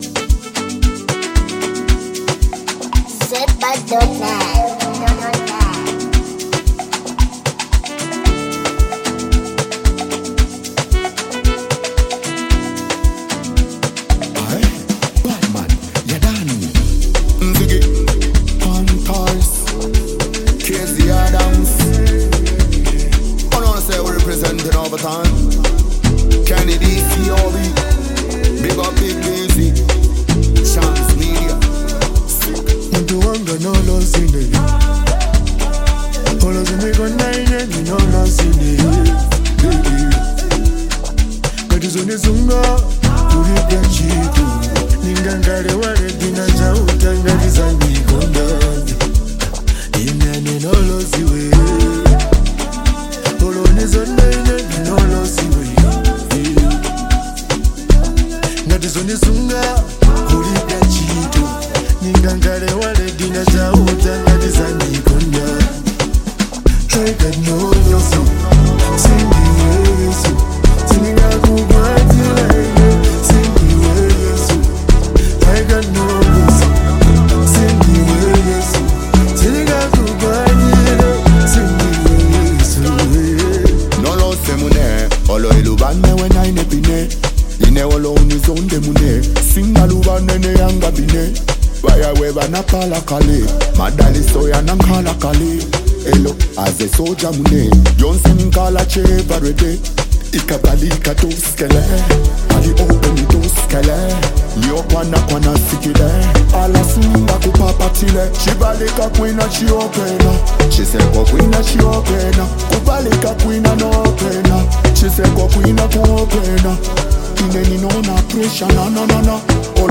Afrobeats Artist • Lusaka, Zambia